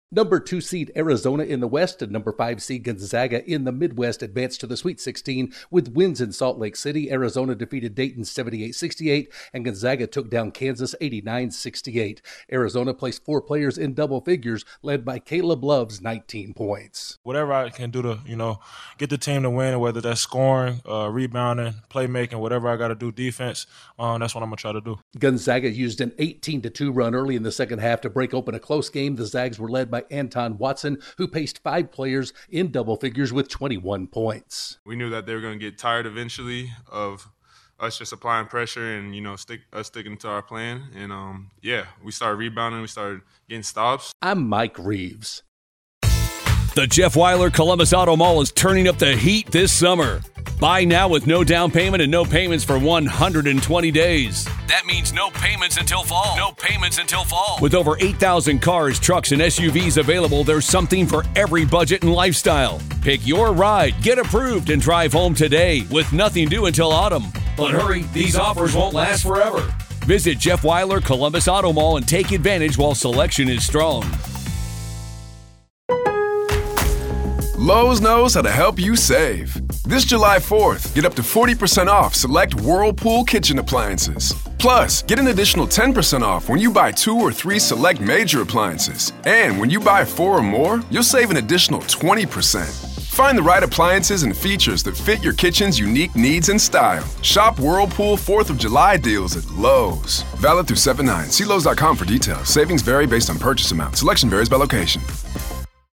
Arizona and Gonzaga come out of Salt Lake City and into the Sweet 16. Correspondent